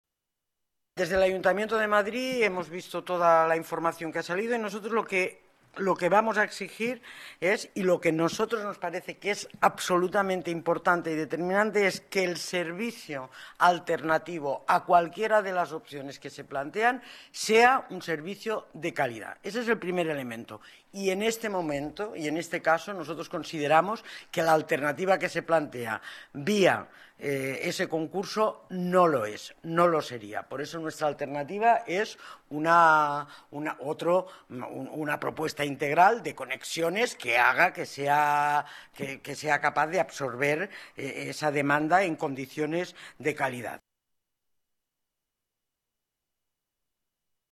Nueva ventana:Declaraciones de Ines Sabanes sobre propuesta Movilidad Obras Linea 1